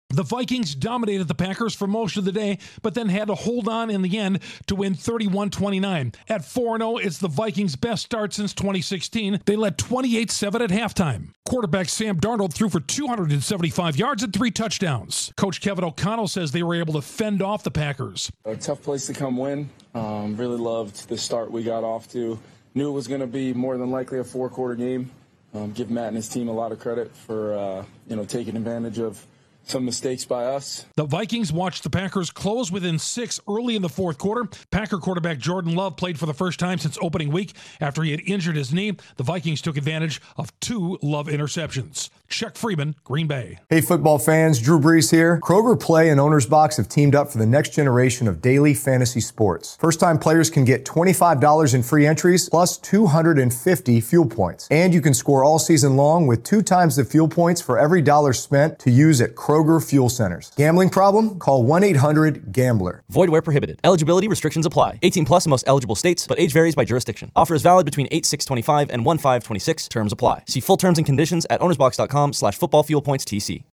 The Vikings ride a strong start to a close win over the Packers. Correspondent